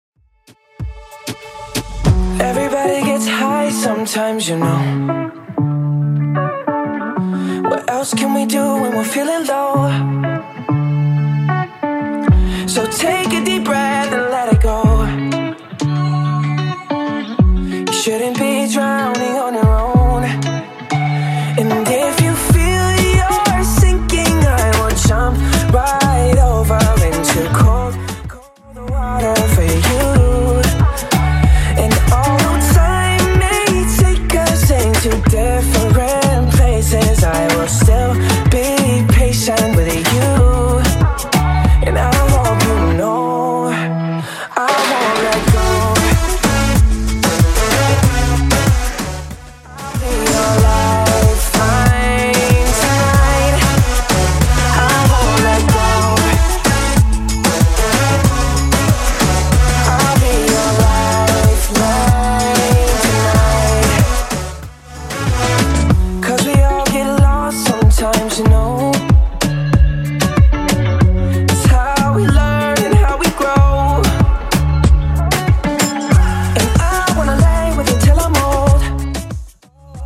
Genre: 70's
BPM: 105